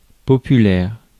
Ääntäminen
Synonyymit foule célèbre Ääntäminen France: IPA: [pɔ.py.lɛʁ] Haettu sana löytyi näillä lähdekielillä: ranska Käännös Ääninäyte Adjektiivit 1. popular US 2.